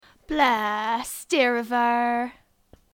Examples of Cornish English
//blɛːst/dɪɻ əv ɛɻ//